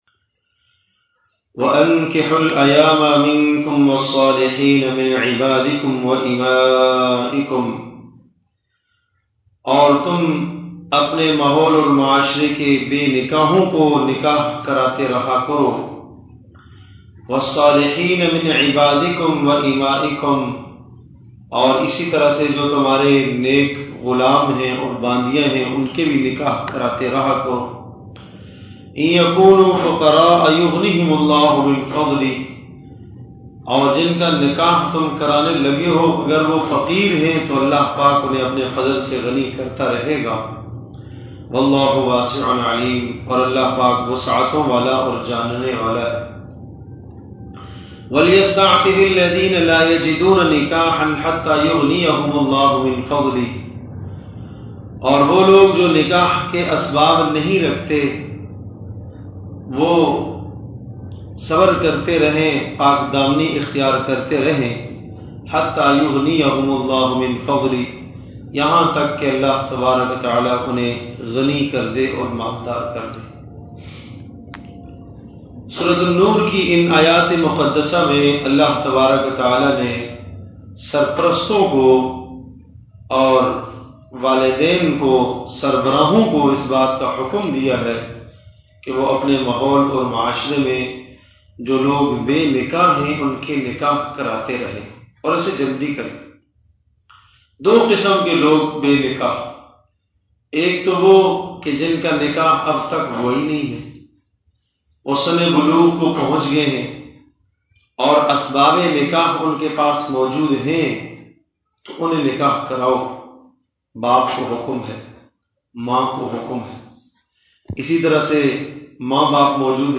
Bayan Section: Sunday Biyannat